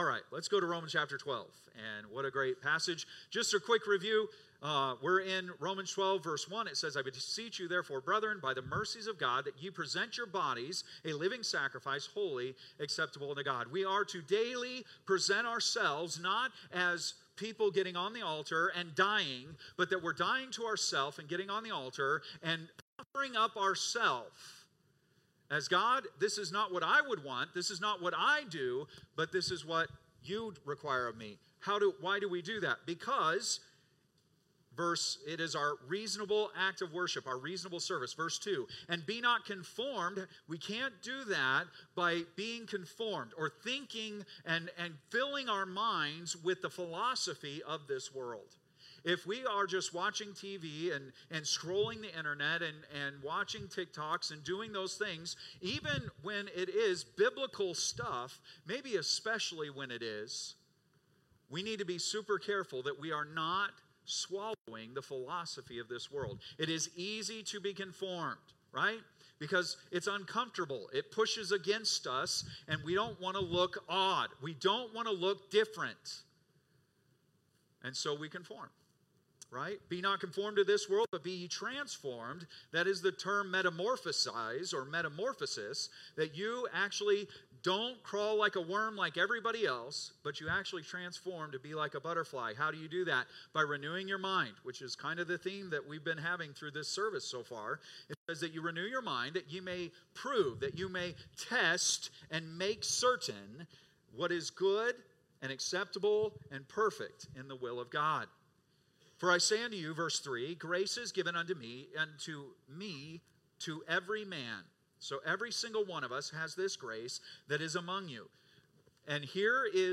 Date: June 29, 2025 (Sunday Morning)